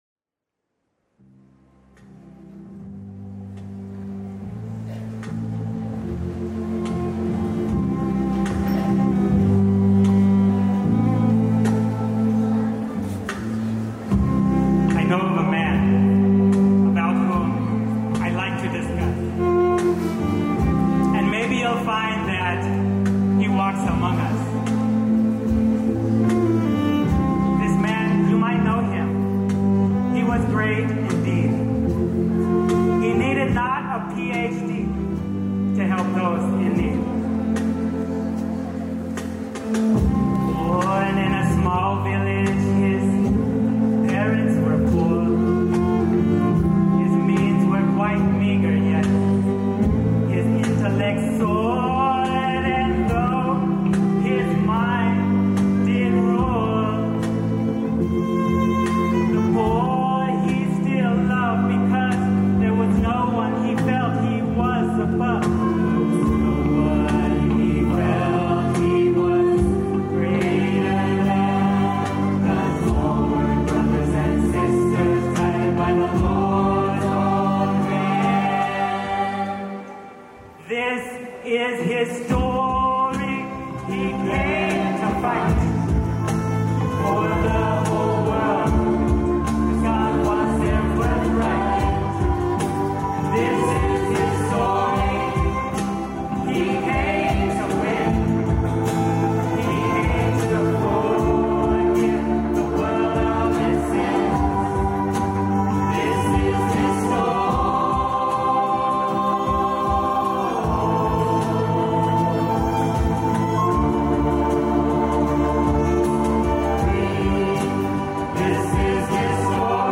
Amritapuri House Band
his-story-live-xmas-2018.mp3